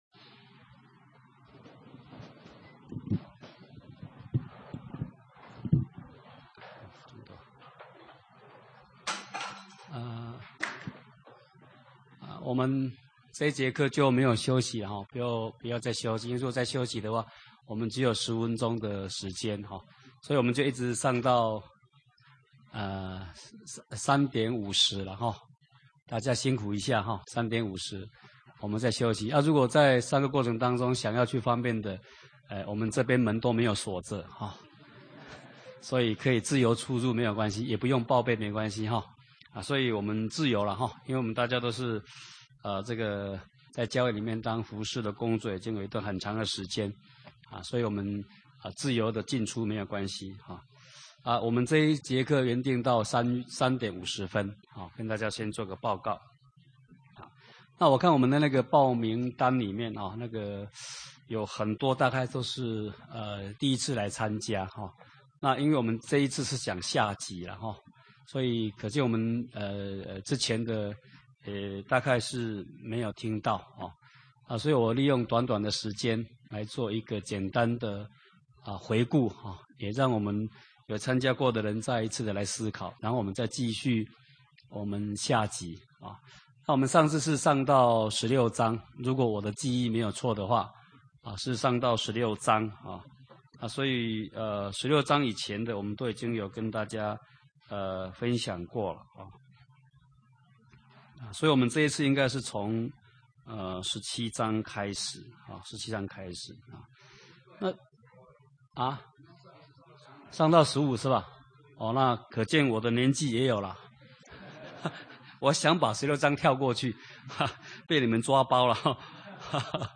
講習會
地點 台灣總會 日期 02/17/2011 檔案下載 列印本頁 分享好友 意見反應 Series more » • 耶利米書44-01：緒論(1